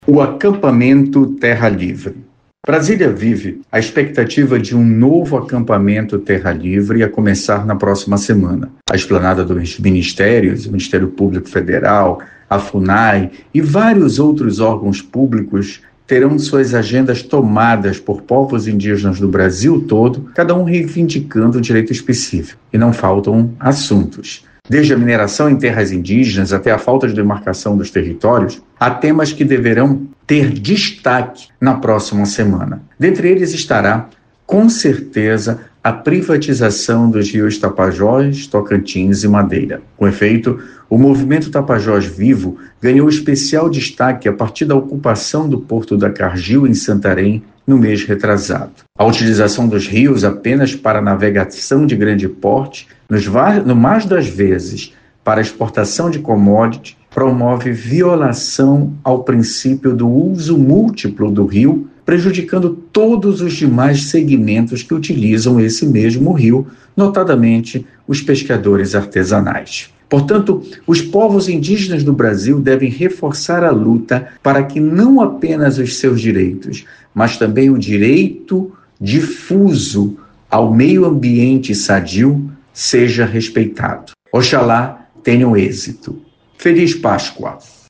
Confira na íntegra o editorial com Procurador Regional da República, Felício Pontes